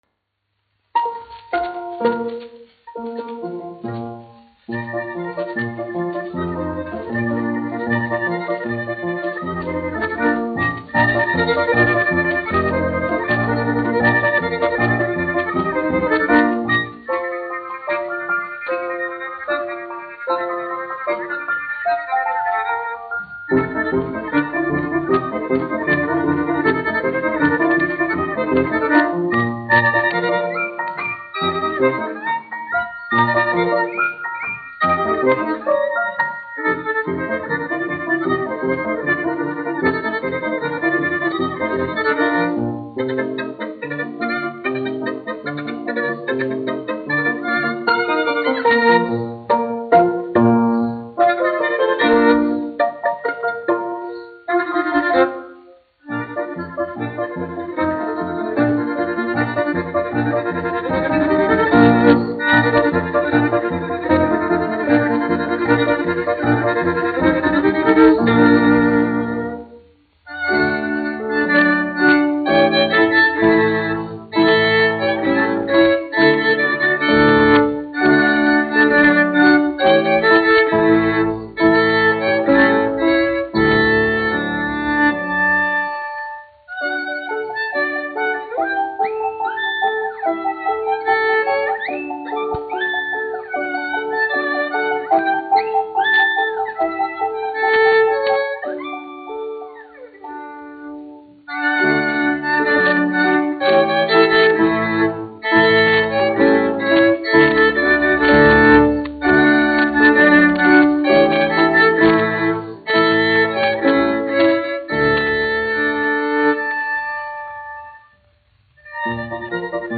1 skpl. : analogs, 78 apgr/min, mono ; 25 cm
Vijoles un klavieru mūzika, aranžējumi
Polkas
Latvijas vēsturiskie šellaka skaņuplašu ieraksti (Kolekcija)